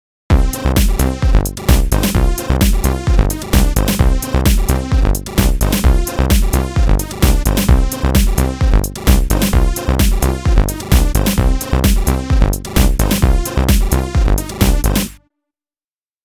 Wobbly retro chip sounds here, but nothing interesting.